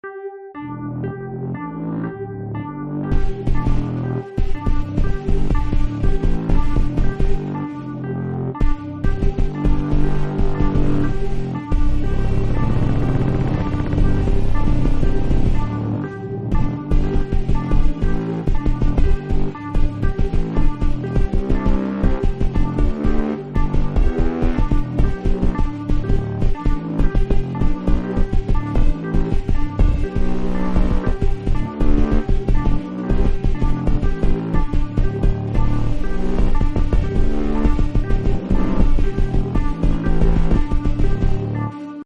experimental electronic, ambient,